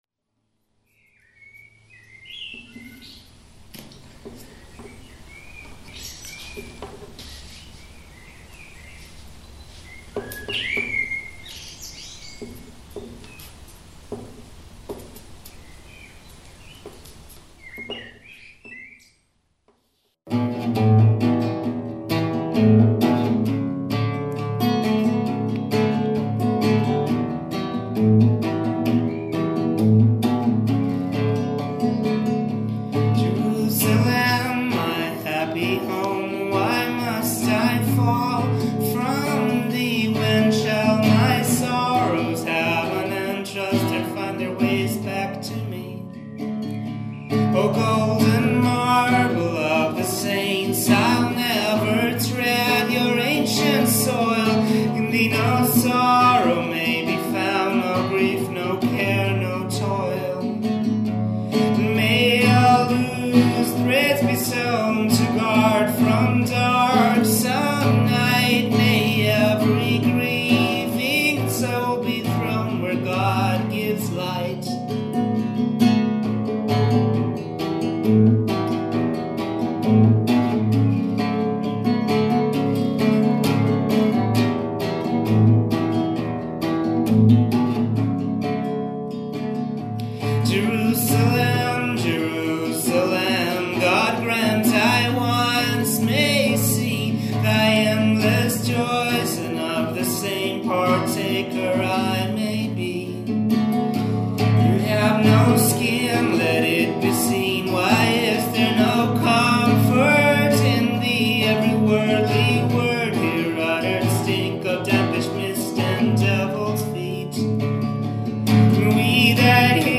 gentle folksiness
more raga instrumentals and extensive psychedelic vamping